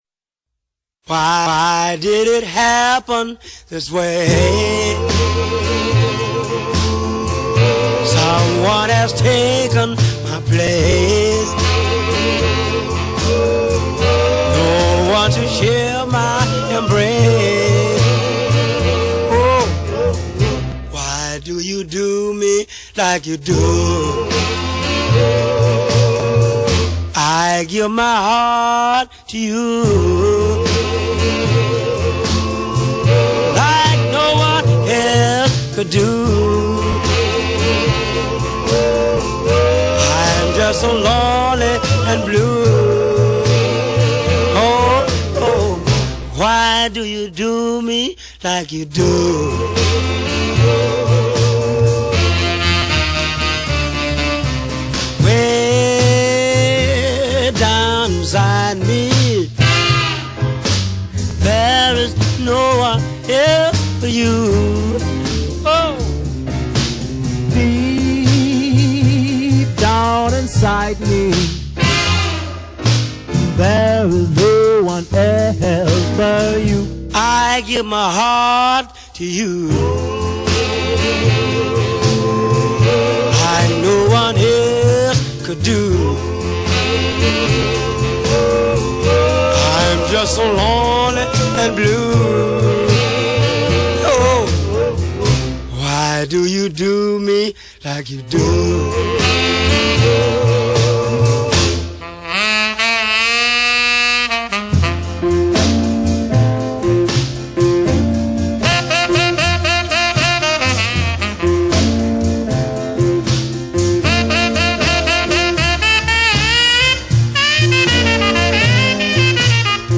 slow beat blues